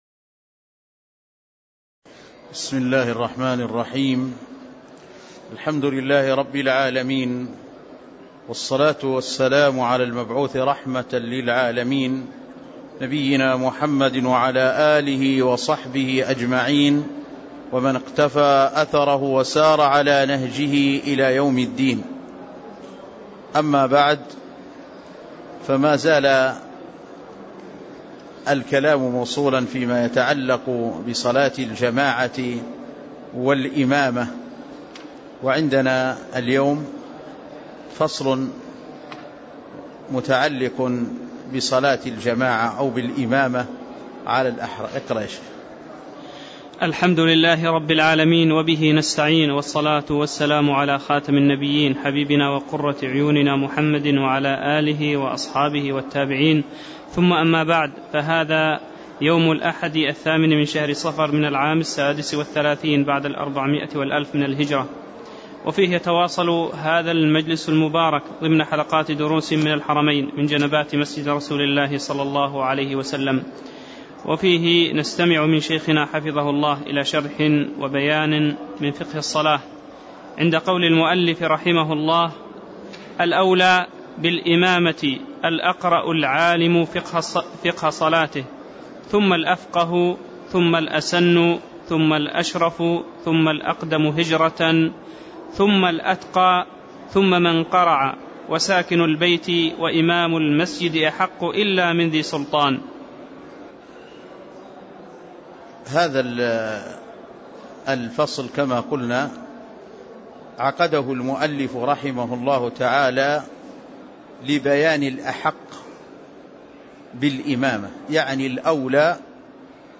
تاريخ النشر ٨ صفر ١٤٣٦ هـ المكان: المسجد النبوي الشيخ